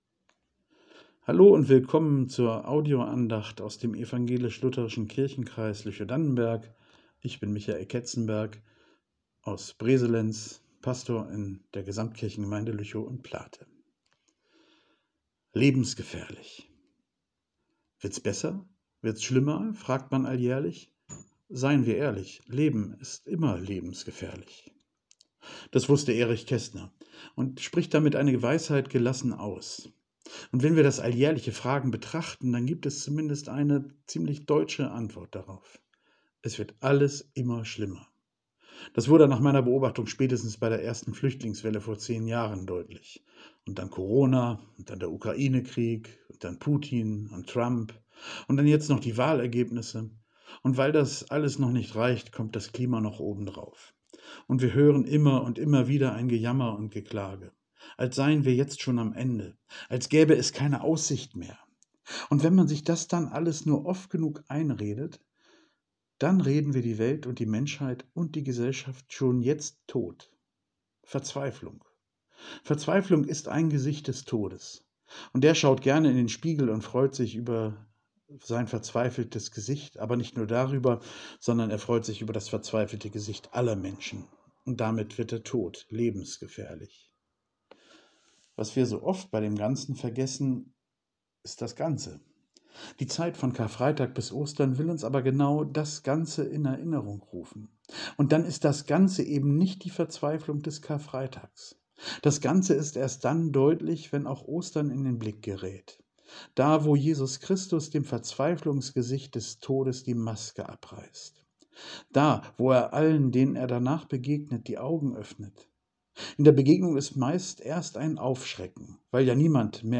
Lebensgefährlich ~ Telefon-Andachten des ev.-luth. Kirchenkreises Lüchow-Dannenberg Podcast